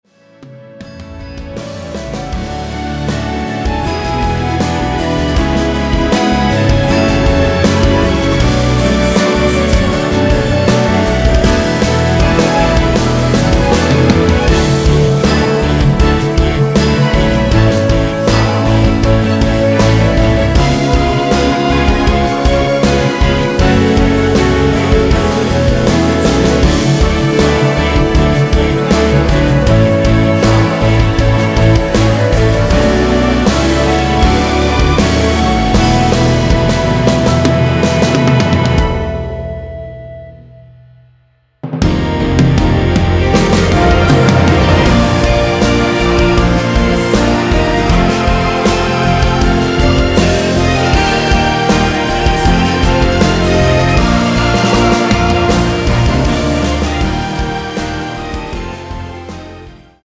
원키에서(-2)내린 멜로디와 코러스 포함된 MR입니다.
앞부분30초, 뒷부분30초씩 편집해서 올려 드리고 있습니다.
중간에 음이 끈어지고 다시 나오는 이유는